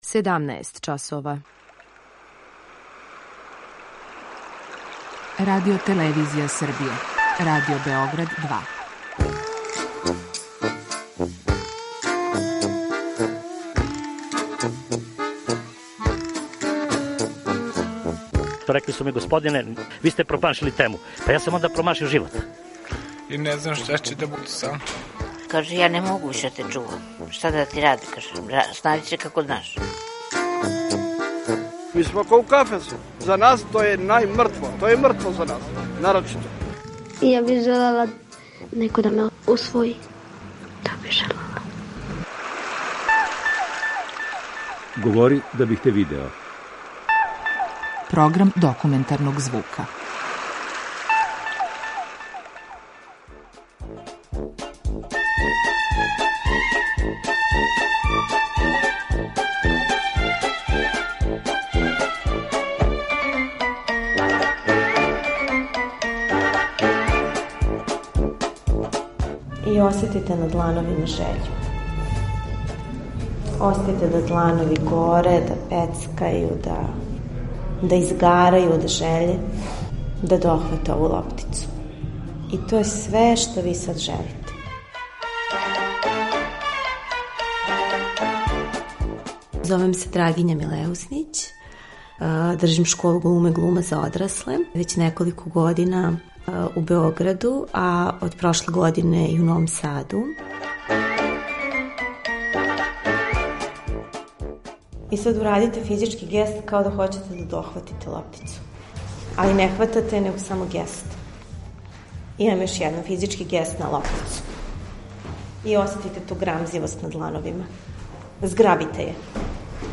Документарни програм
Слушаоци ће такође имати прилике да чују тонске записе са драмске радионице, као и изјаве њених полазника.